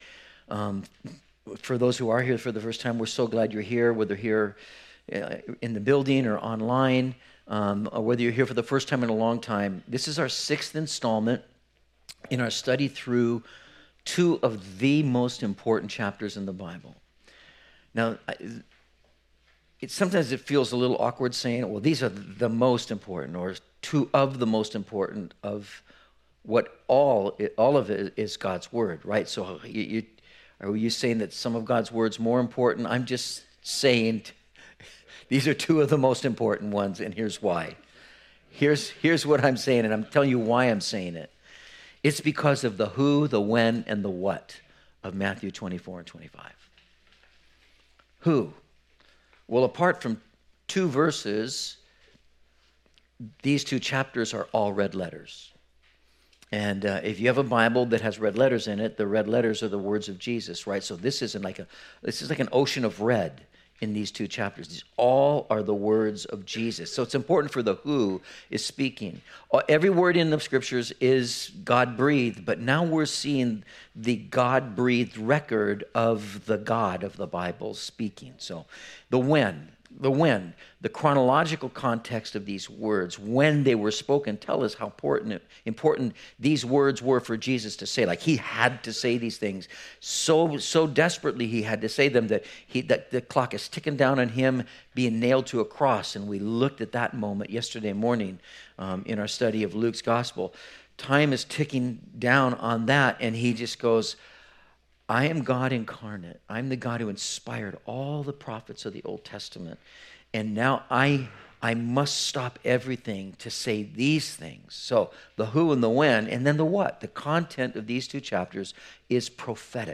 04/01/19 Lessons from a Thief and Two Servants - Metro Calvary Sermons